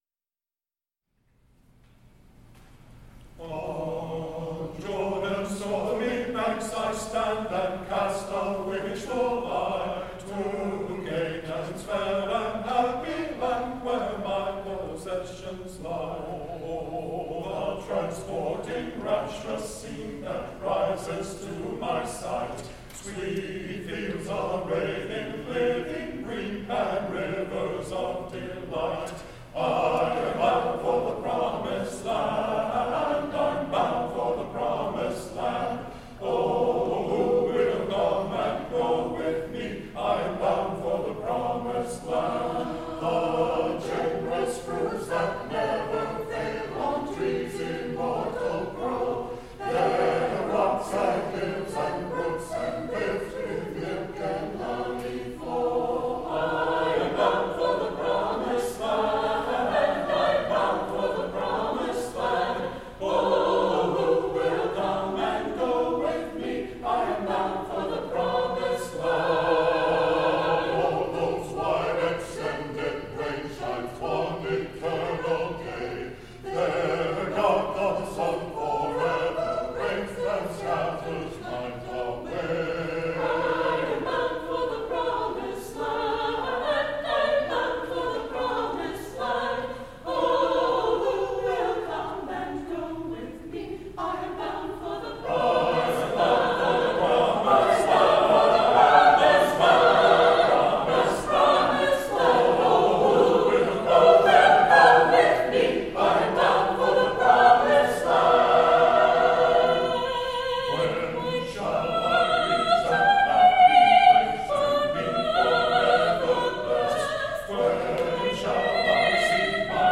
Promised Land - Sanctuary Choir
The Central Sanctuary Choir performs "The Promised Land
inspiring anthem highlights the lenten themes of journey
features a solo